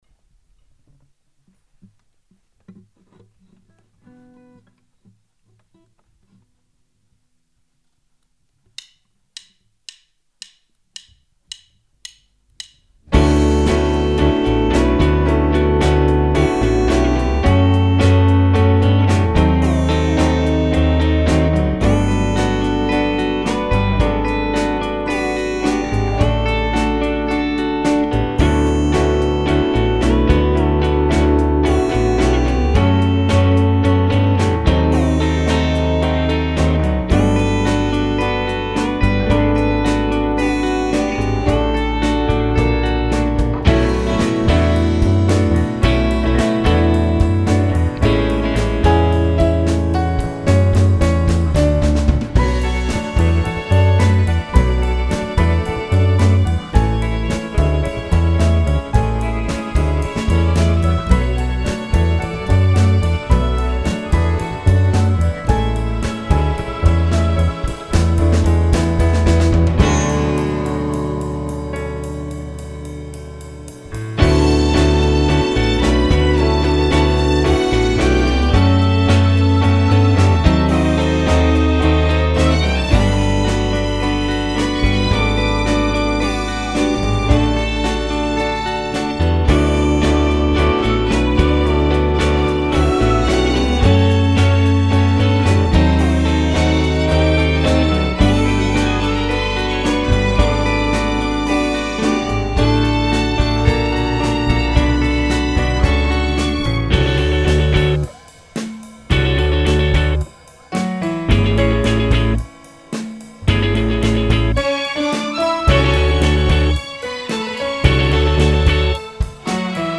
inedito dalle sessioni in studio